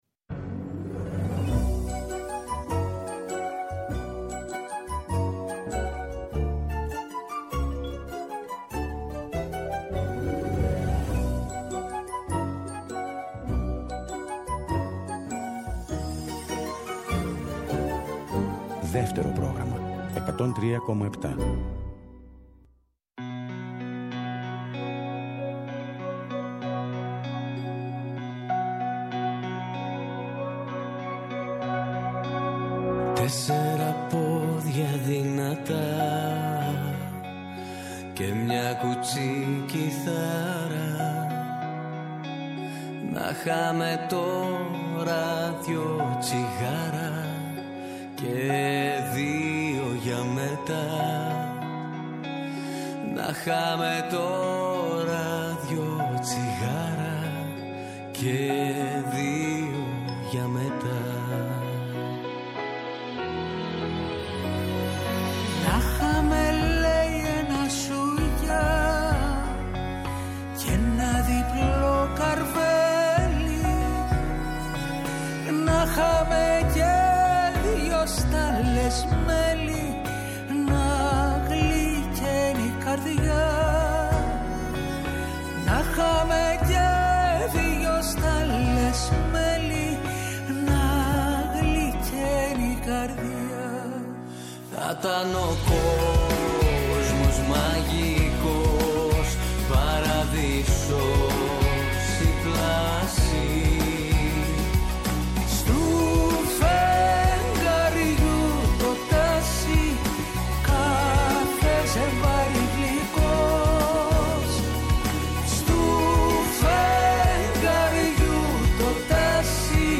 «Άλλη μια μέρα» στον αέρα του Δεύτερου, εκπομπή καλής διάθεσης και μουσικής, για την ώρα που η μέρα φεύγει και η ένταση της μέρας αναζητά την ξεκούραση και τη χαρά της παρέας.